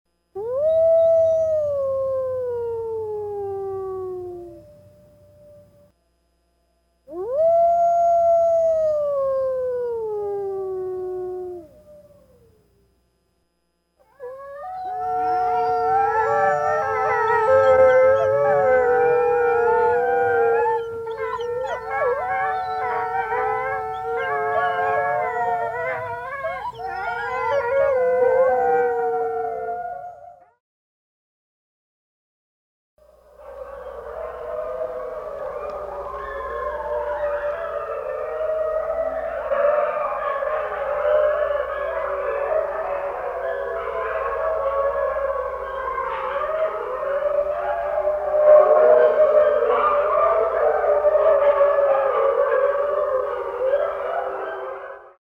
SFX – HOWLS (VARIOUS)
SFX-HOWLS-(VARIOUS).mp3